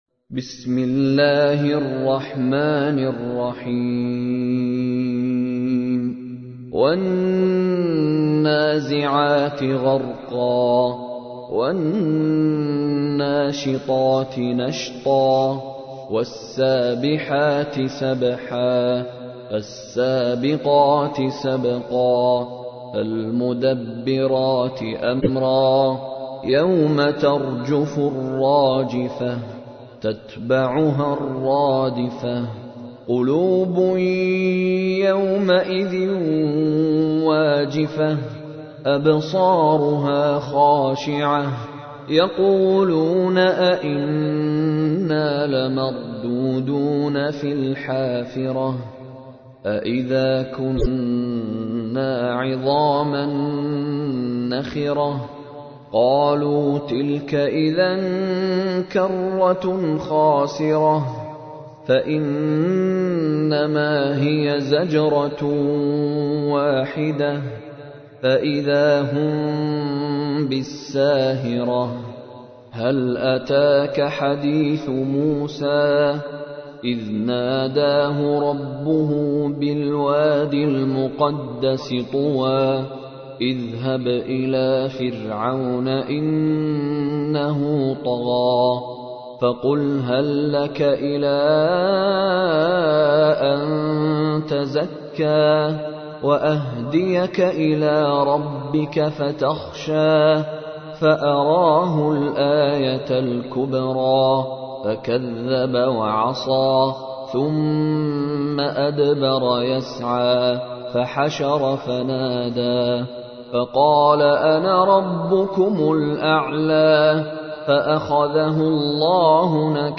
تحميل : 79. سورة النازعات / القارئ مشاري راشد العفاسي / القرآن الكريم / موقع يا حسين